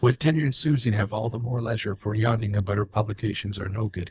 text-to-speech
Expected Output: The output of this action is a URL to the generated audio file, which contains the synthesized speech.